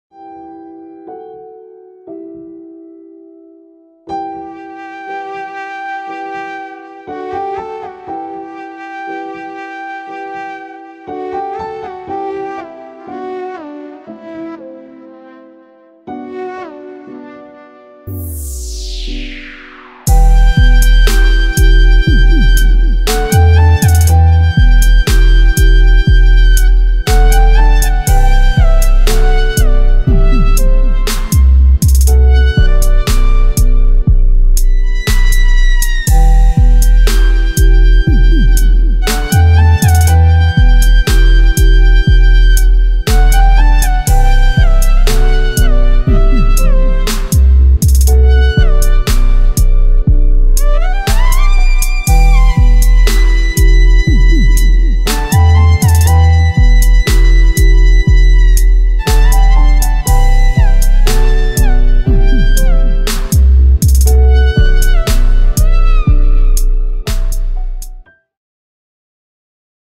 Скачать музыку / Музон / Восточные песни и музыка